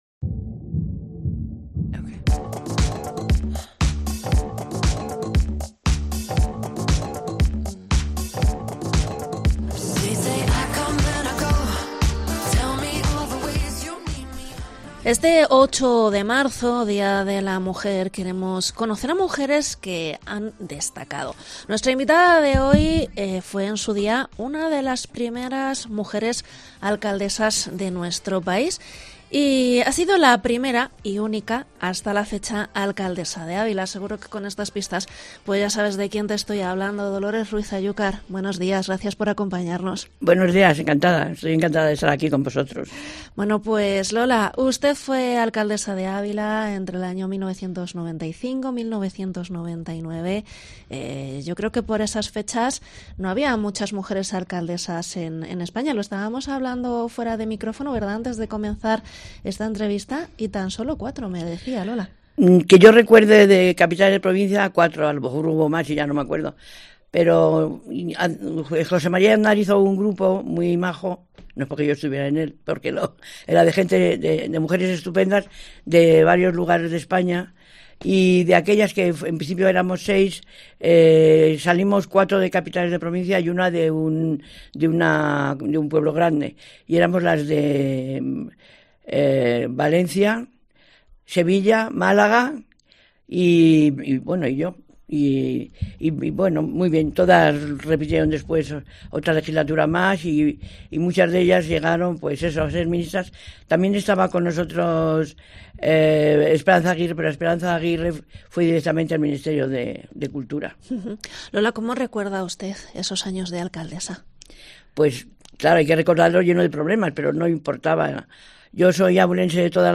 ENTREVISTA a Lola Ruiz-Ayúcar, primera y única mujer alcaldesa de Ávila